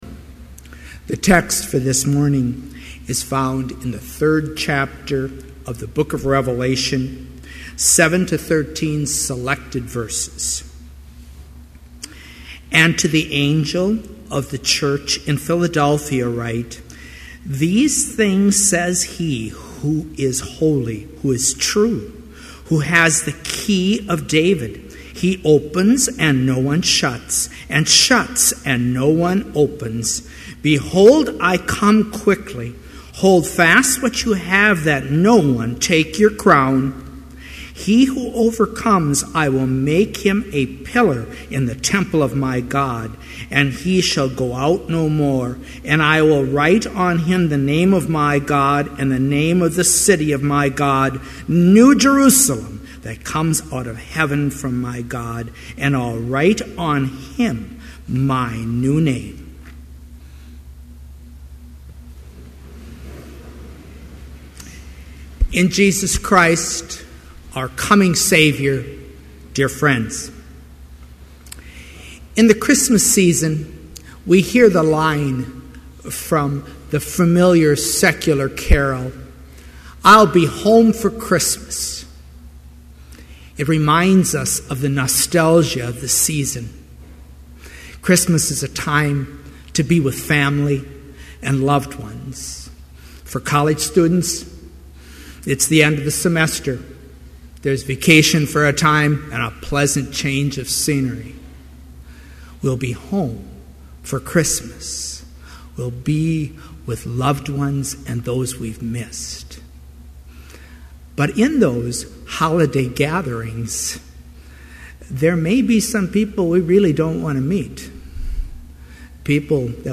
Complete service audio for Chapel - December 1, 2011